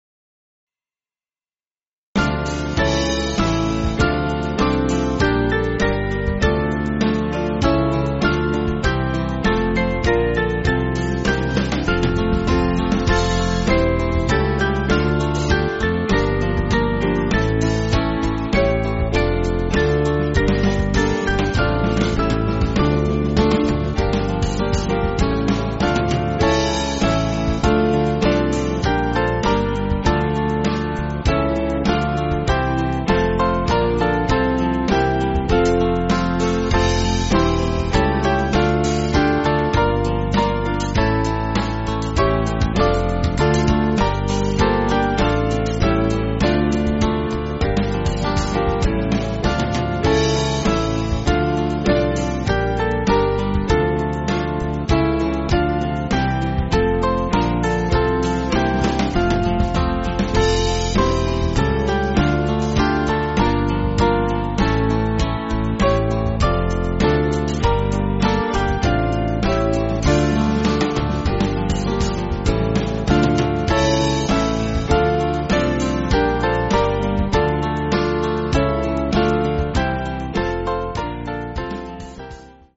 Small Band
(CM)   6/Dm